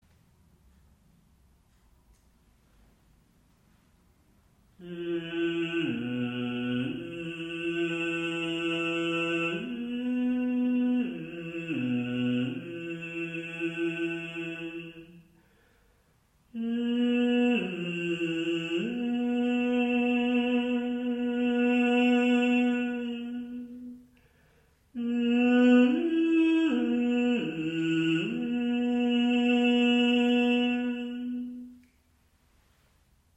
胸声の練習
４度下の胸声を響かせる練習です。
ファの音でドローンを響かせています。